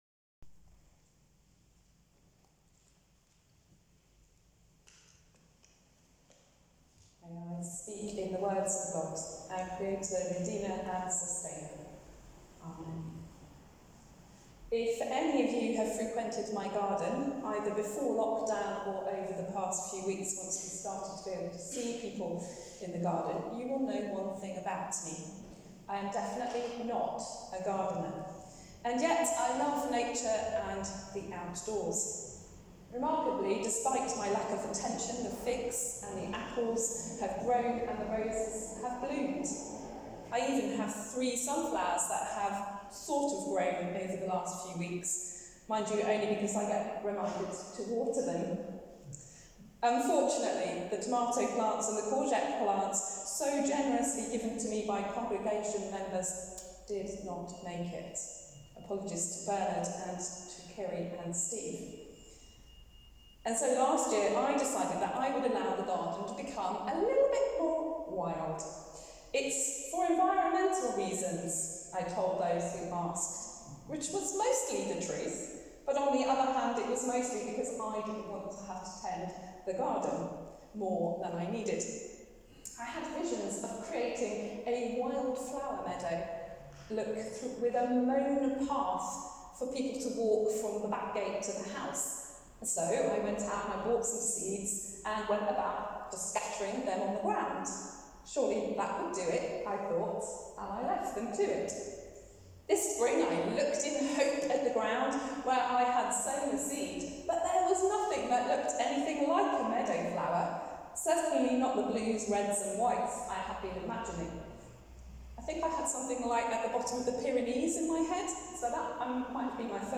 Sermon: God the Sower | St Paul + St Stephen Gloucester